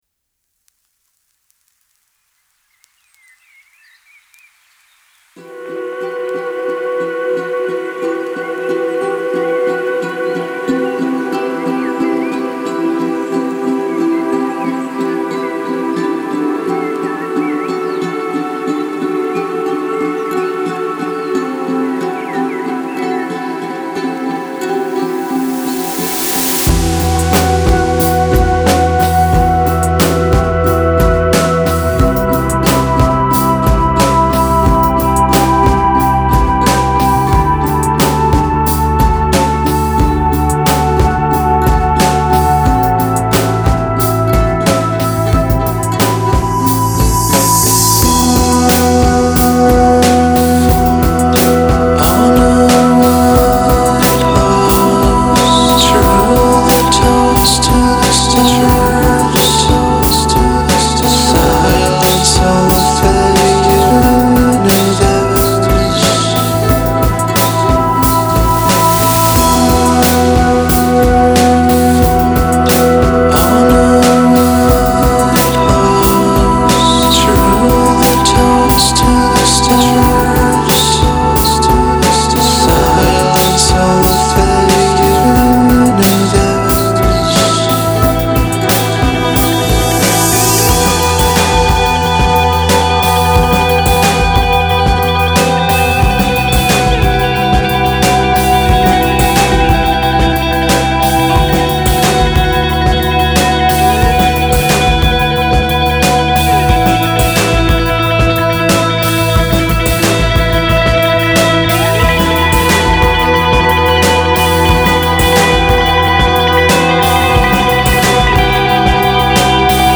dream pop ou du slowcore
compositions mélancoliques,cotonneuses et scintillantes